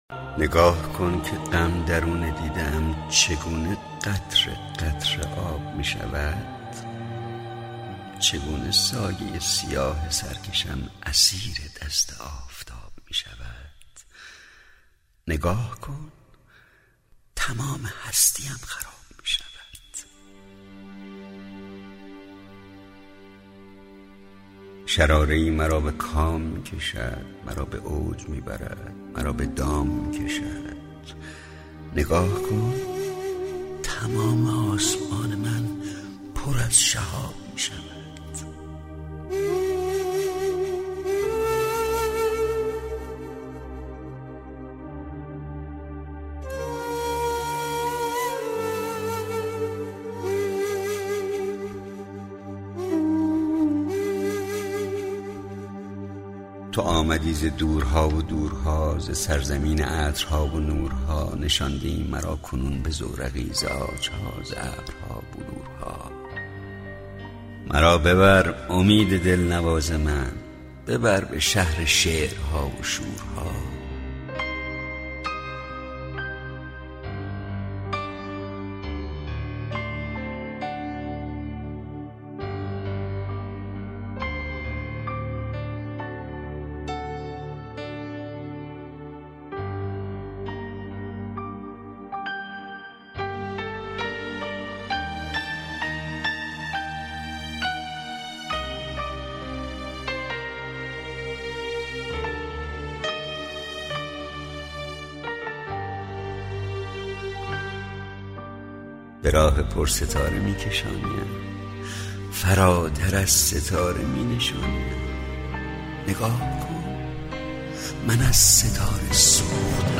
دانلود دکلمه نگاه کن با صدای خسرو شکیبایی با متن دکلمه
اطلاعات دکلمه
گوینده :   [خسـرو شکیبایی]